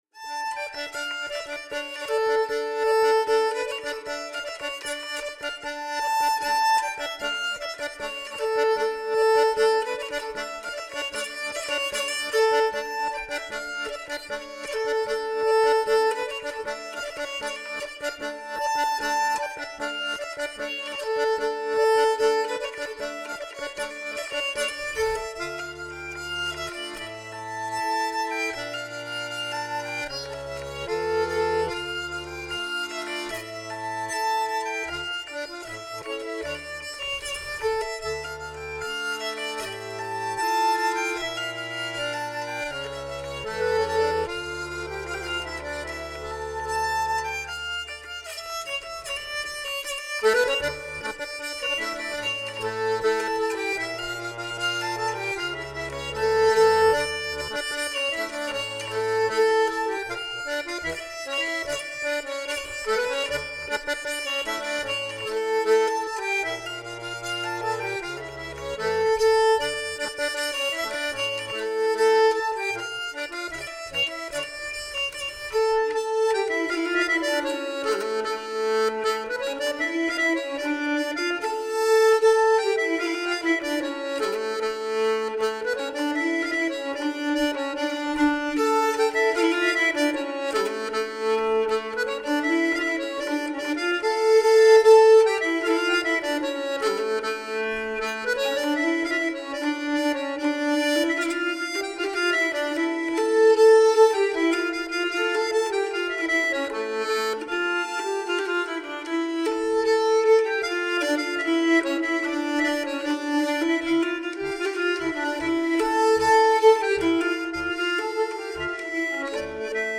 Nykelharpa, Geige
Akkordeon, Oktavmandoline, Obertonflöte
Scottis-från-Idre.ogg